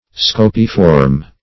Search Result for " scopiform" : The Collaborative International Dictionary of English v.0.48: Scopiform \Sco"pi*form\, a. [L. scopae, scopa, a broom + -form.] Having the form of a broom or besom.